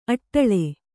♪ aṭṭaḷe